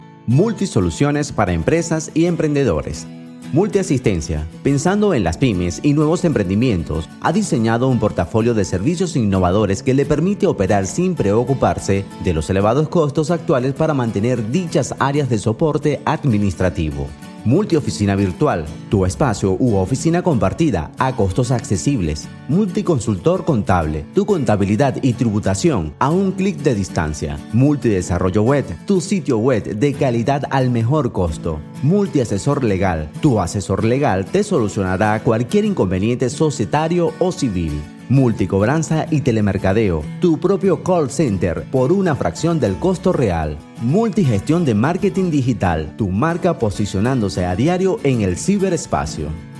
Voz para video corporativo.